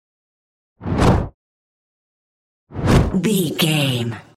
Whoosh deep fast x2
Sound Effects
Fast
dark
intense
whoosh